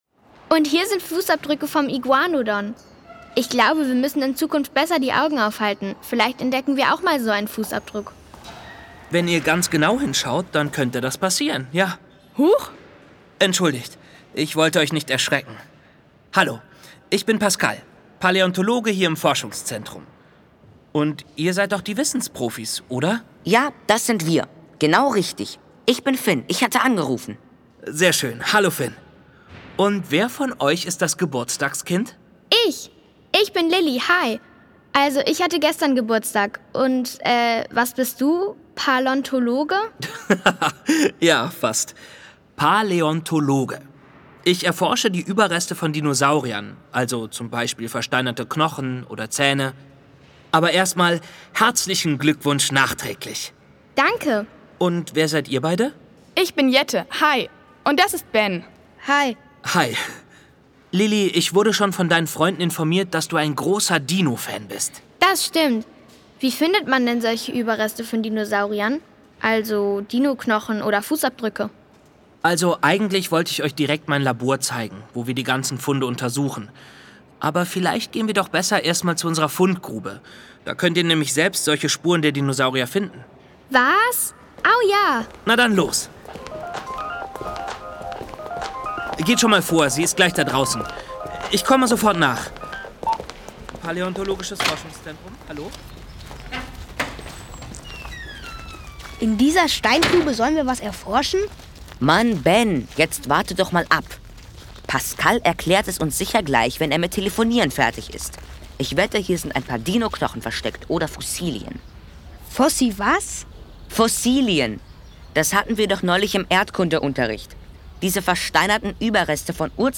Schlagworte Ausgrabungen • dinopark • Dinosaurier • Dinosaurierpark • Evolution und Erforschung • Kindgerecht • Leben der Dinos • Sachhörbuch für Kinder • Sachthemen für Kinder • Tyrannosaurus Rex • Urzeit • Urzeittier • Wieso?